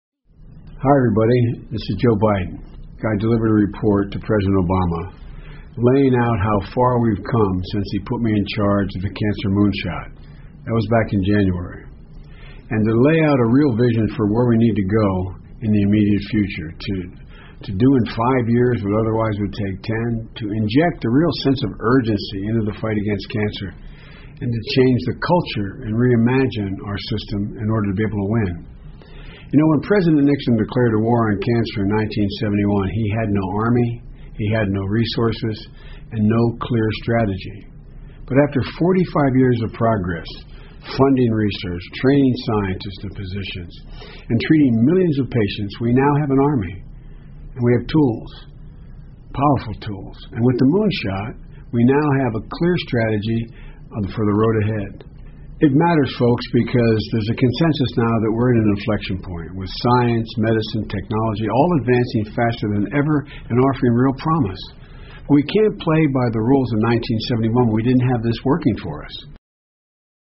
在线英语听力室奥巴马每周电视讲话：实现治愈癌症登月计划的任务（01）的听力文件下载,奥巴马每周电视讲话-在线英语听力室